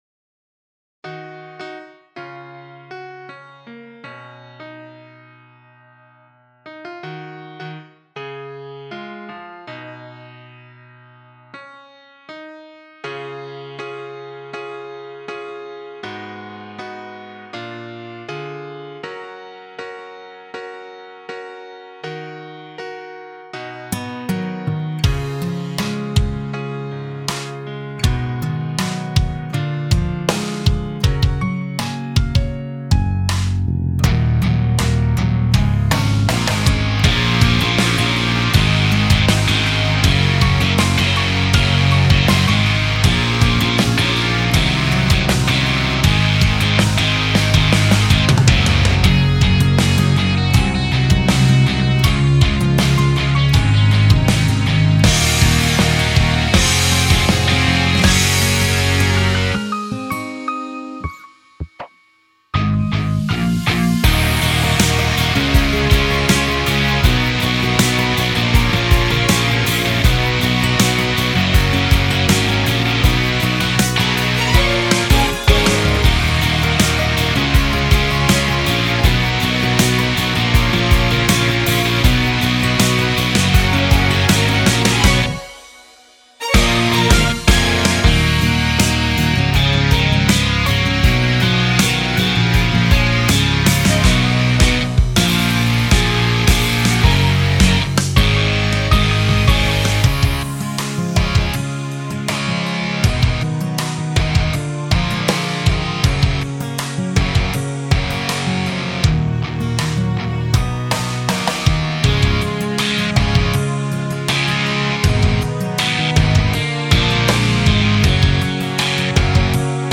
ロック
インスト版（カラオケ）